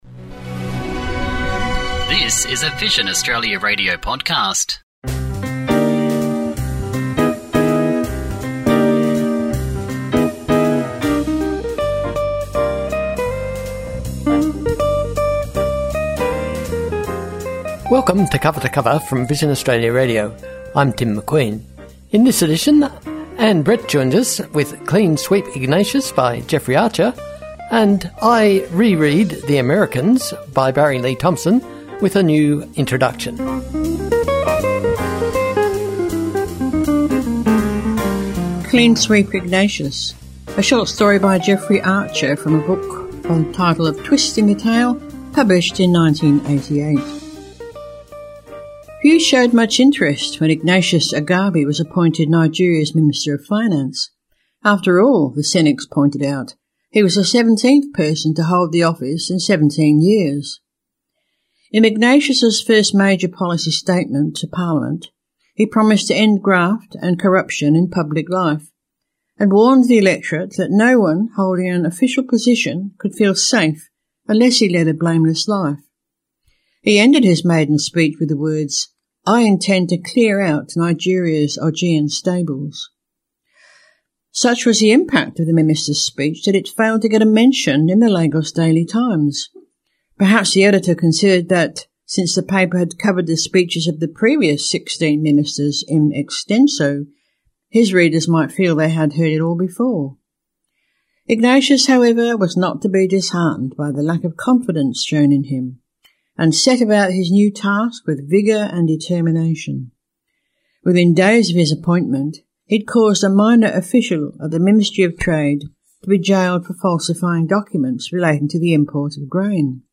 That blog post was featured on episode 472 of Cover to Cover on Vision Australia Radio on 12 May, along with a new reading of the story itself.
‘My’ section starts 15 minutes and 30 seconds in – though of course I’m not suggesting you should miss out on hearing the first reading, a short story by Jeffrey Archer taken from his 1988 collection A Twist in the Tale.